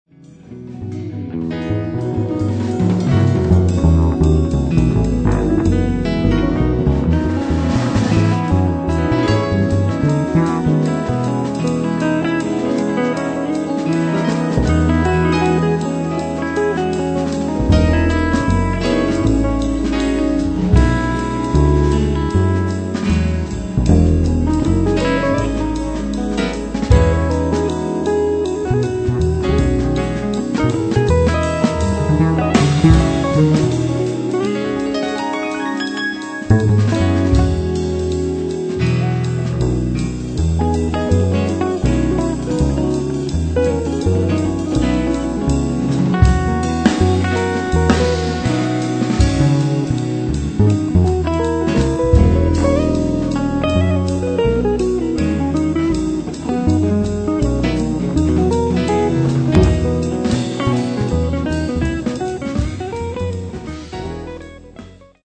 and bass, guitars, keyboard, sax and trumpet.